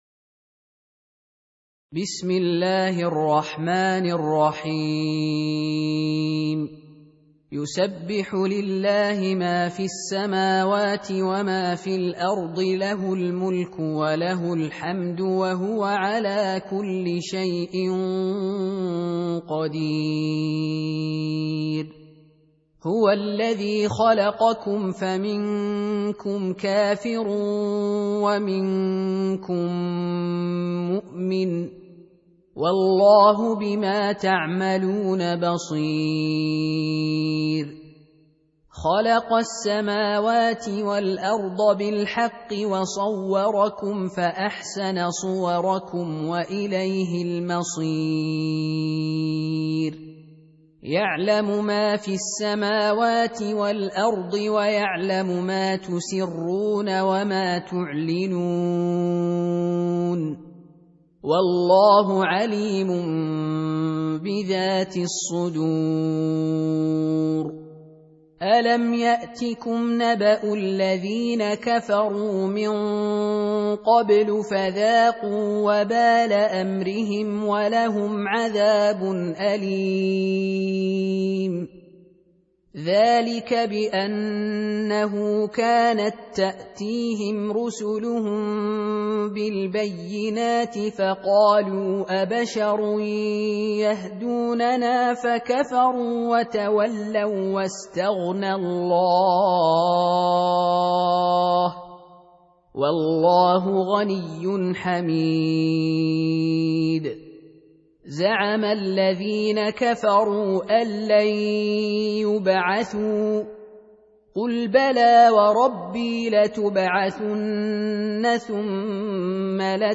64. Surah At-Tagh�bun سورة التغابن Audio Quran Tarteel Recitation
Surah Repeating تكرار السورة Download Surah حمّل السورة Reciting Murattalah Audio for 64.